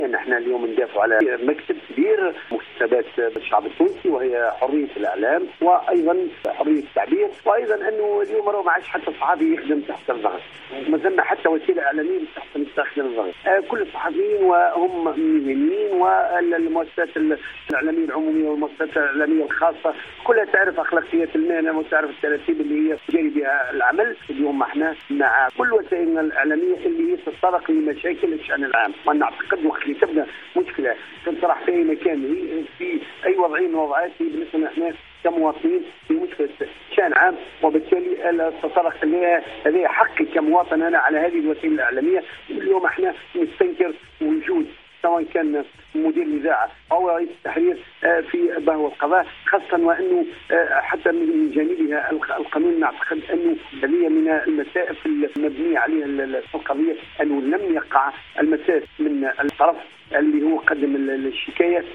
وبالتزامن مع هذه الجلسة، نفذ عدد من اعلاميي ولاية مدنين ونشطاء المجتمع المدني وقفة مساندة امام المحكمة، رفضا لاي وصاية على الاعلام وتاكيدا على ضرورة احترام حرية التعبير التي تعد من ابرز مكاسب الثورة التونسية.